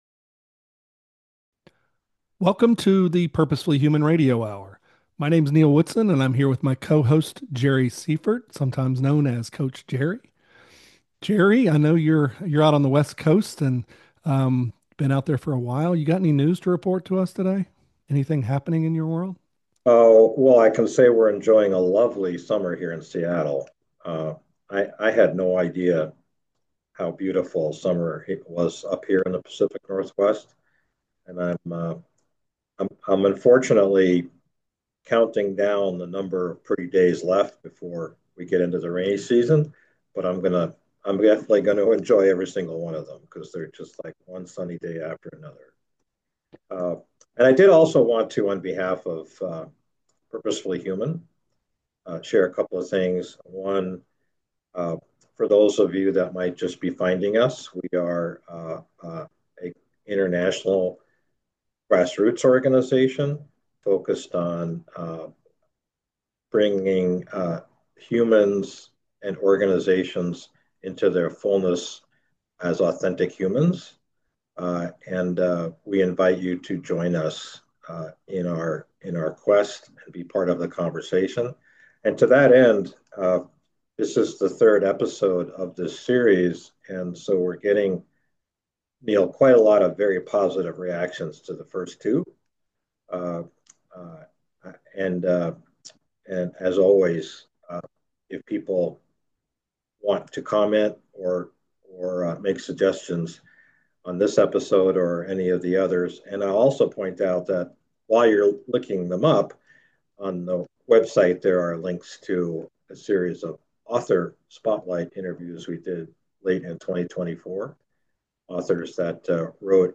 In the PurposeFully Human Radio Hour we talk to interesting people about the need for more humanity in our world and get their ideas for moving in that direction.